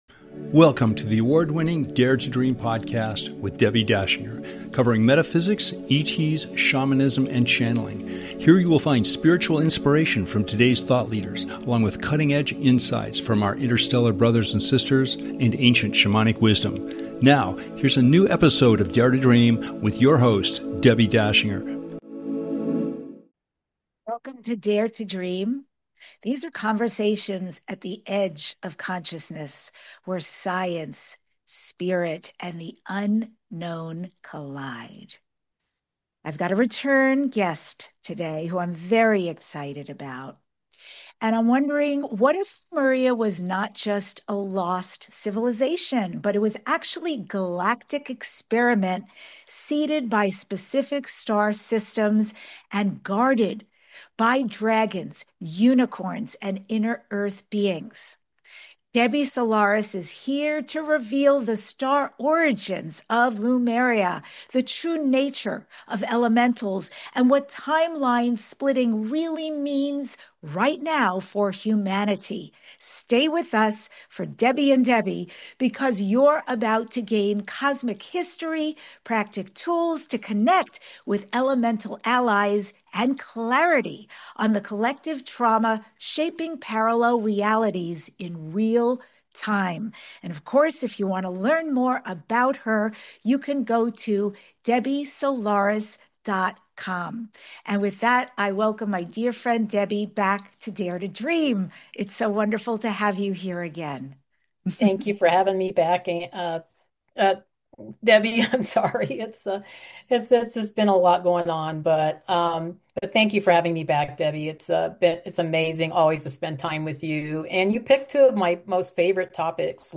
Talk Show Episode
In this fascinating conversation, we explore the galactic origins of Lemuria, the role of extraterrestrial star civilizations, and the mysterious elemental beings that once lived alongside humanity. Discover how advanced humans may have lived in a fifth-dimensional society thousands of years ago, working with crystal technology, telepathy, and multidimensional travel.